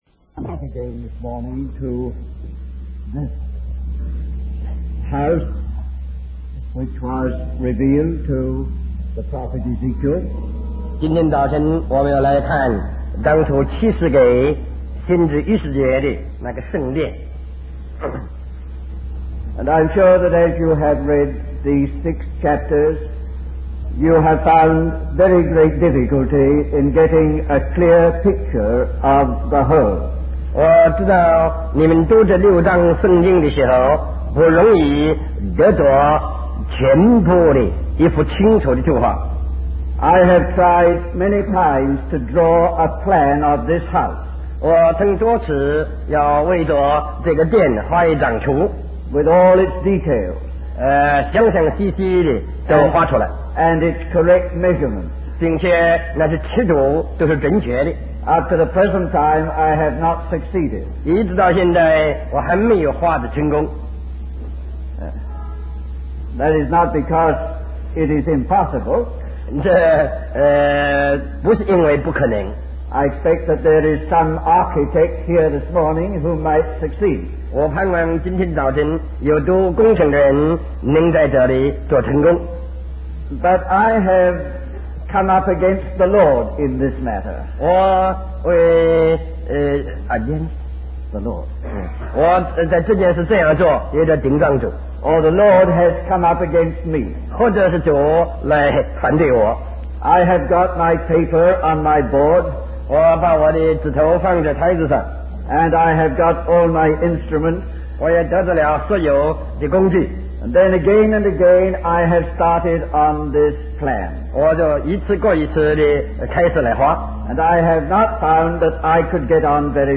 In this sermon, the speaker emphasizes the importance of being filled with the Holy Spirit.